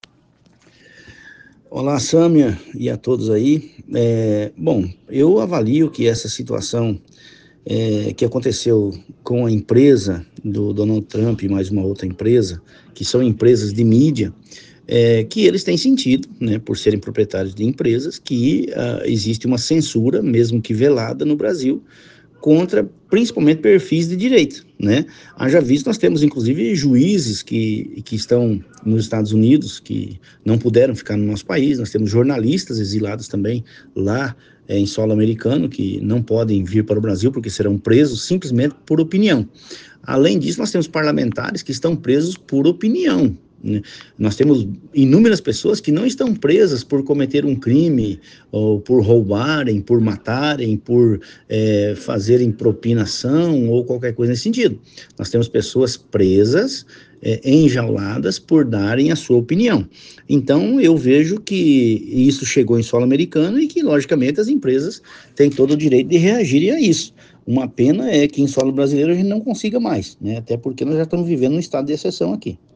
OUÇA A ENTREVISTA DO DEPUTADO GILBERTO CATTANI